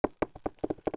CREAK_Subtle_02_mono.wav